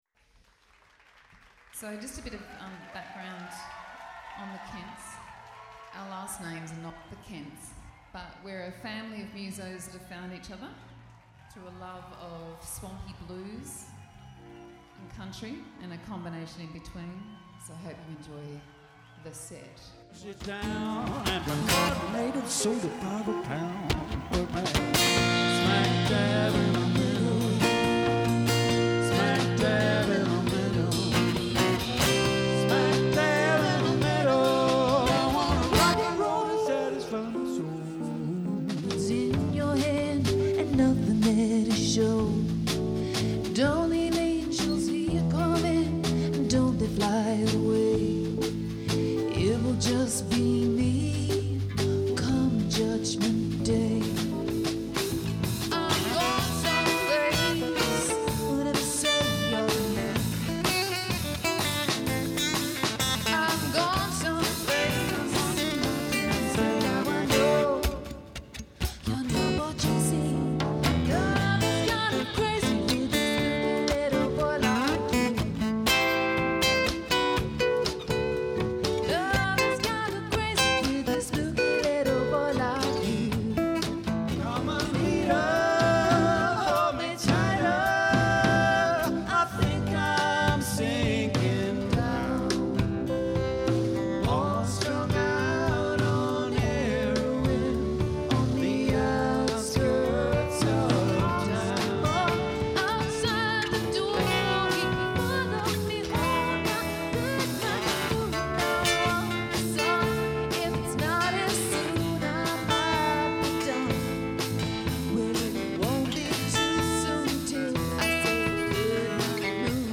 Deep cuts • Lesser‑known gems • Cheeky charm